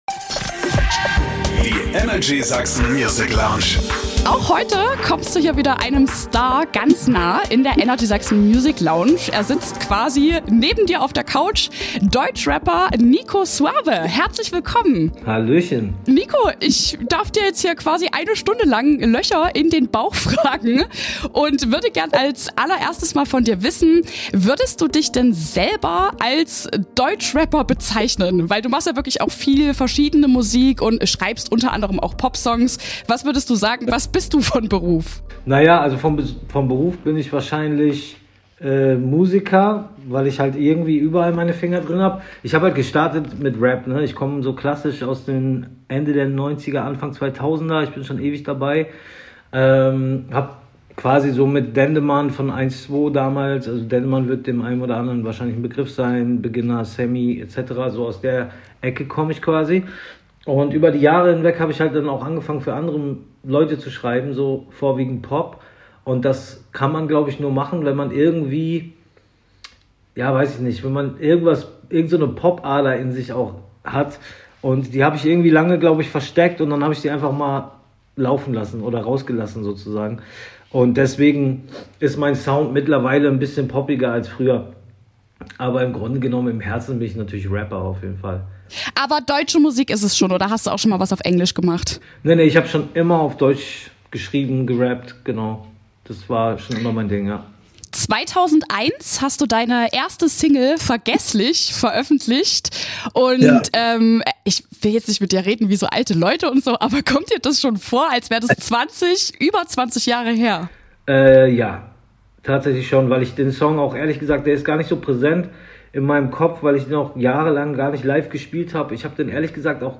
Mittlerweile schreibt er aber auch Popsongs für die ganz Großen in Deutschland und ist nebenbei auch noch Papi von 3 Kindern. Vor allem durch seine Ehrlichkeit ist das Interview super spannend geworden. Nico Suave plant auch bald wieder eine Tour und will auf jeden Fall auch in Sachsen vorbei kommen.